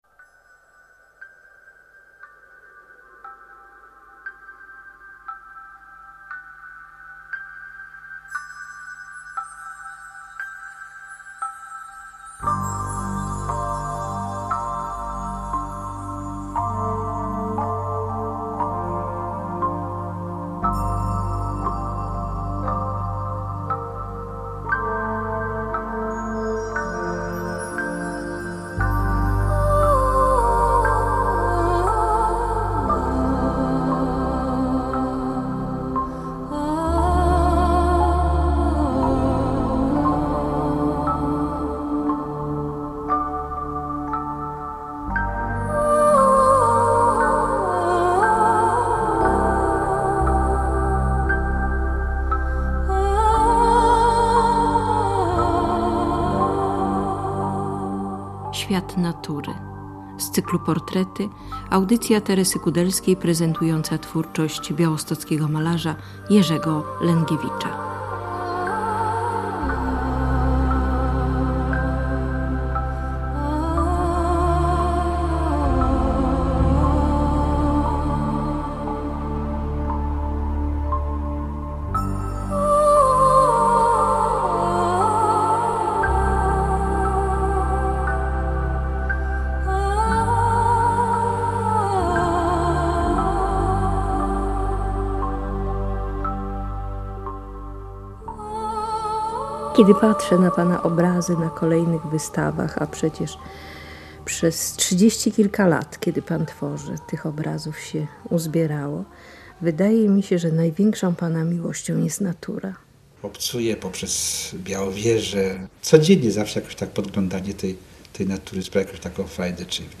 Radio Białystok | Reportaż | Białostoccy mistrzowie sztuk pięknych na falach eteru | 7.